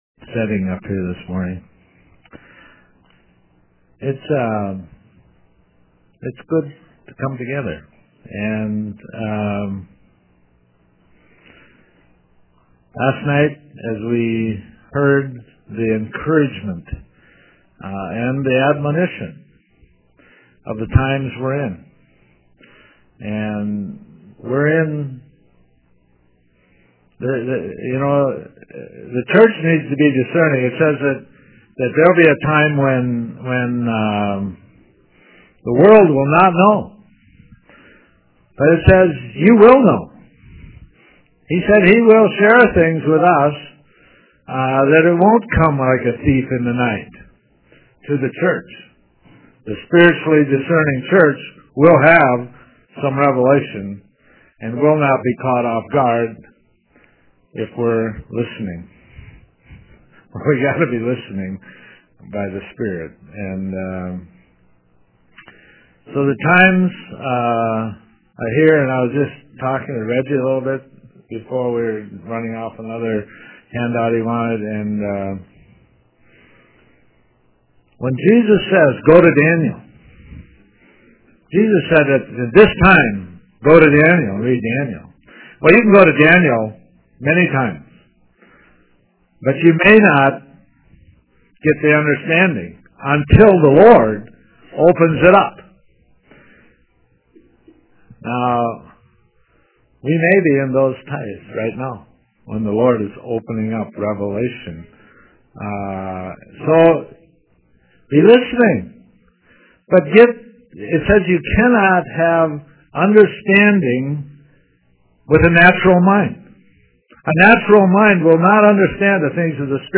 2012 Feast of Tabernacles Session 2